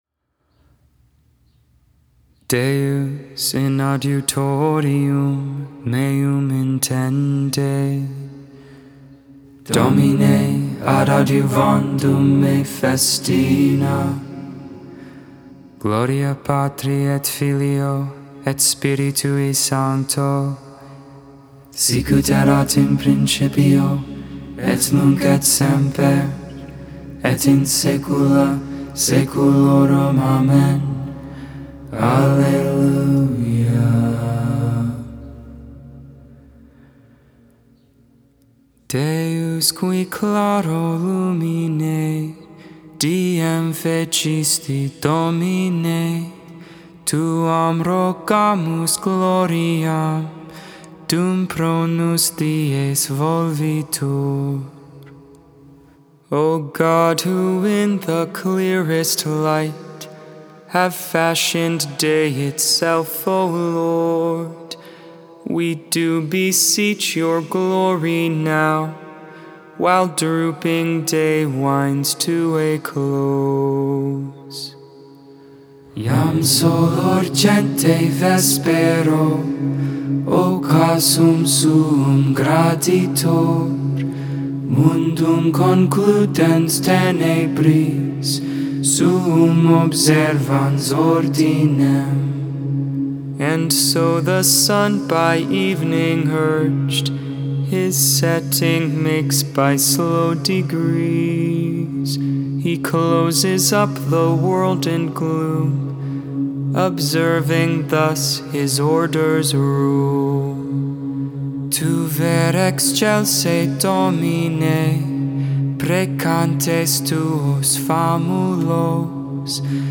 9.30.21 Vespers, Thursday Evening Prayer